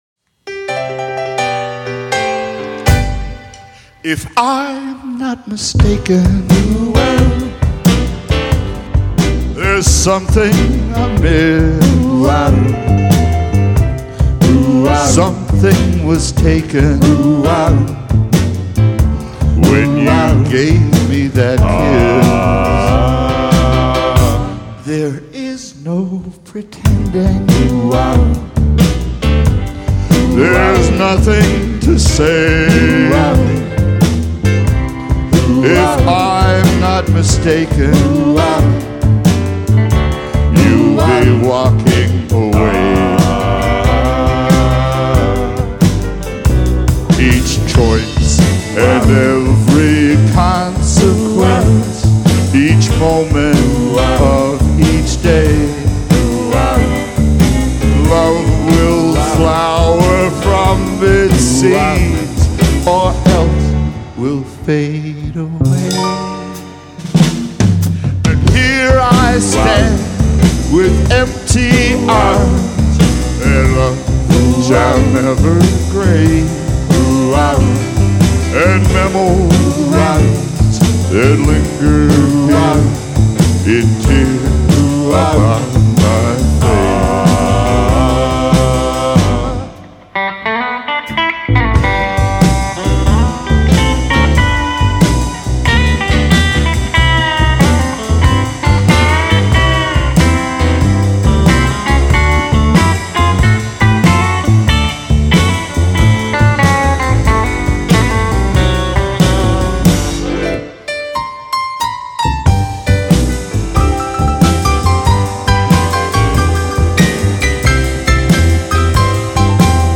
live album
The album was recorded on June 24th, 2023 in Bonavista, NL.
Guitars, Harmonica, Vocals
Vocal & Keys
Banjo & Vocals
Drums & Vocals
Bass & Vocals